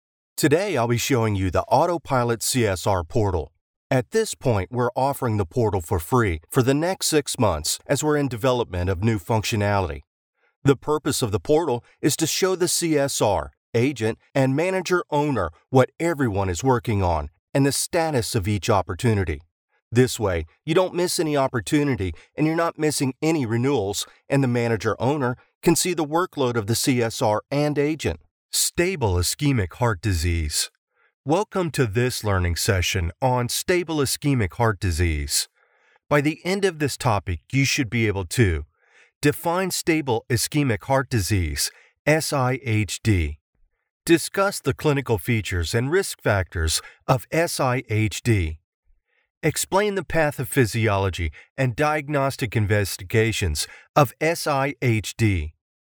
Demo
southern us
standard us
authoritative
smooth
professional home studio